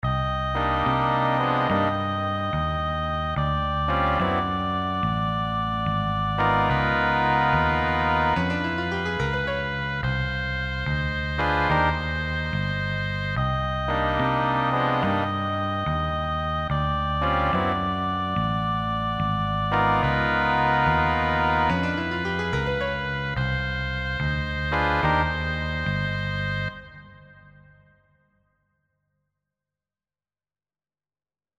4.3-  Arrangement avec section de cuivres (cours) + (vidéo) + (ex. 1 à 6) + (corrigé des ex. 4 et 6) + (mp3 de l'ex. 4) + (
Avec section de cuivres corrige 6.mp3